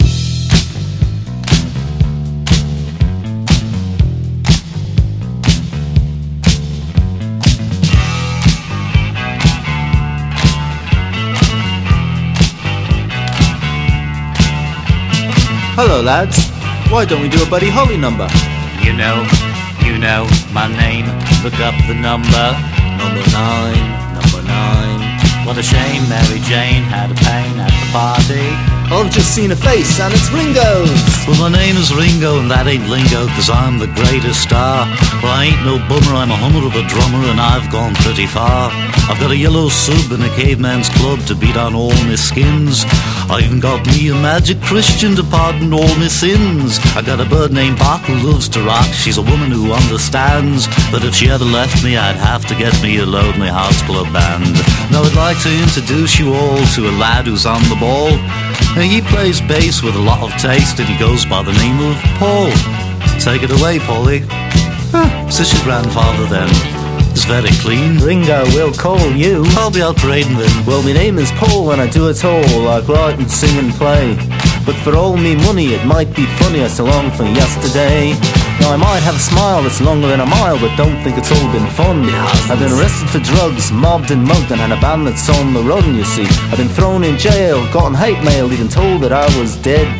EASY LISTENING / OTHER / OLDIES / ROCK / GOOD TIME MUSIC
ハッピーでオールド・タイミーなコーラス入り！